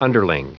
Prononciation du mot underling en anglais (fichier audio)
Prononciation du mot : underling